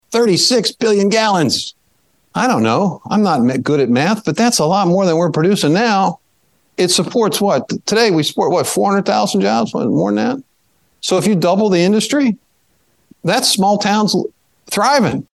(NATIONAL ASSOCIATION OF FARM BROADCASTING)- US Agriculture Secretary Tom Vilsack told attendees of Growth Energy’s annual Hill Summit (Sept. 11-14, 2023), it’s a “make or break moment” for the biofuels industry when it comes to Sustainable Aviation Fuel.